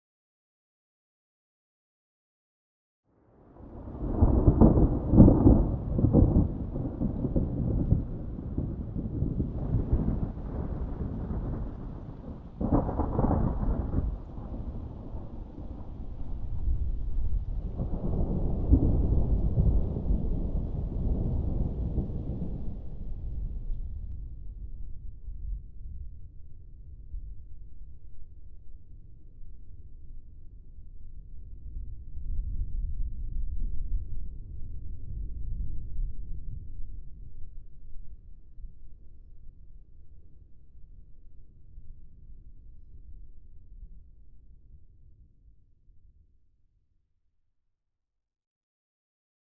thunderfar_1.ogg